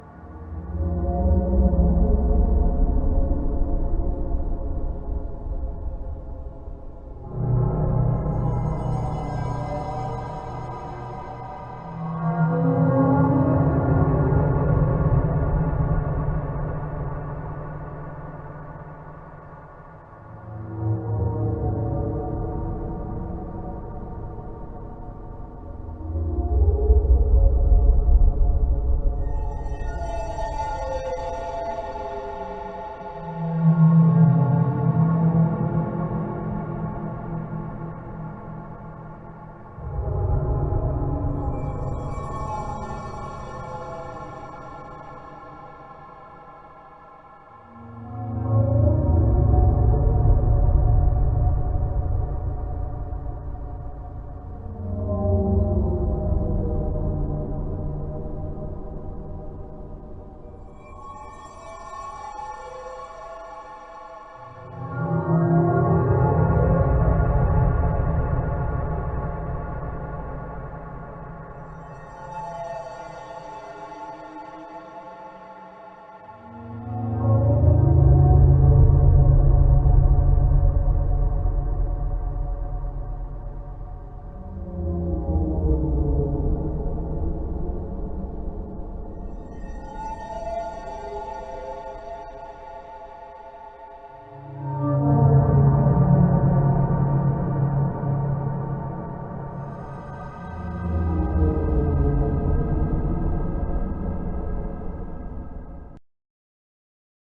Атмосферные звуки Фнаф 2